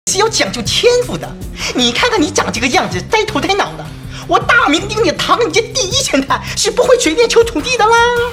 Filmisk krigsfilmsröst för rå berättarröst
Intensiv AI-röst för krigsfilm
Upplev en rå, auktoritär AI-röst designad för filmiskt krigsberättande, stridsdokumentärer och intensiv trailerröst.
Text-till-tal
Noiz.ais krigsfilmsröst erbjuder en djup, resonant ton som fångar tyngden och brådskan i en konflikt.